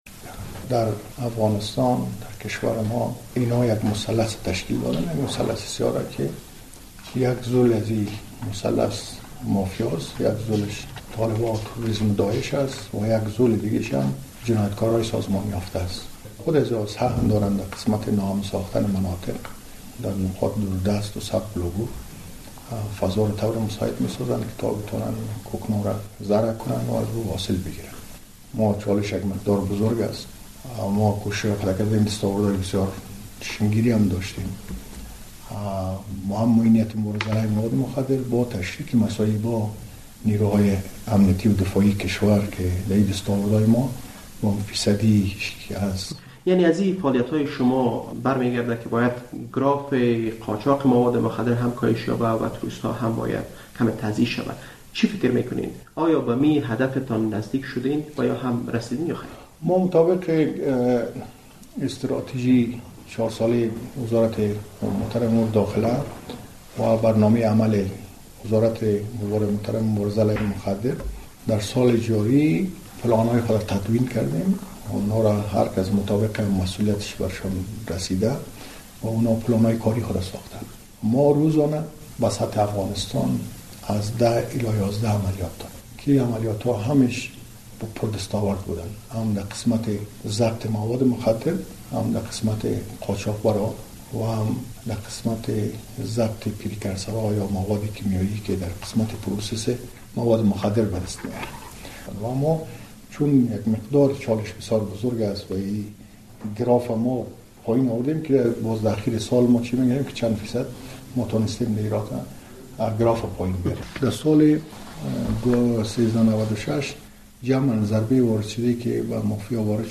عبدالخلیل بختیار معیین مبارزه علیه مواد مخدر وزارت داخله